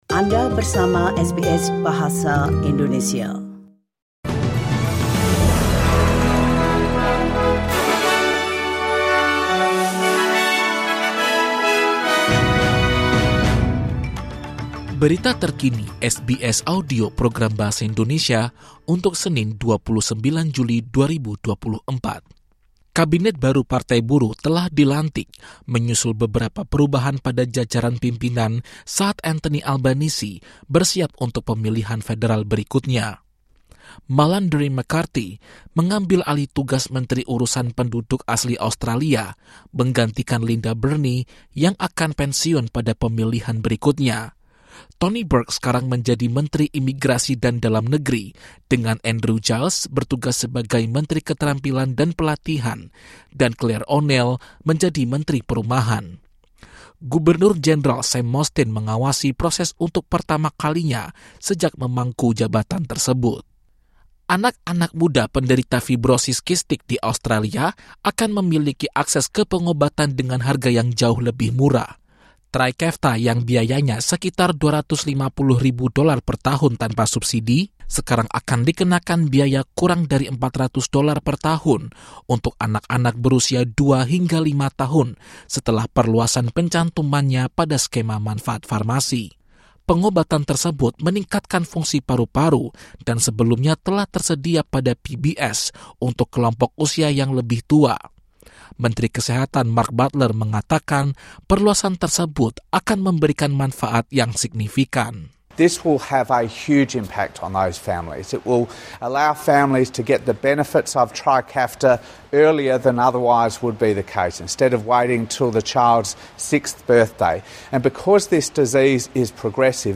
SBS Audio news in Indonesian - 29 July 2024